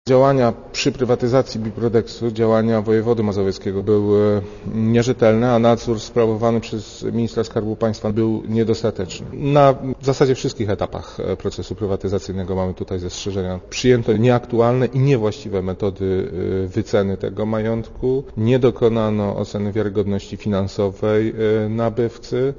Firma, która kupiła biuro Biprodex, rozpoczęła działalność już po rozpoczęciu prywatyzacji - powiedział wiceprezes Najwyższej Izby Kontroli Krzysztof Szwedowski.
* Mówi Krzysztof Szwedowski*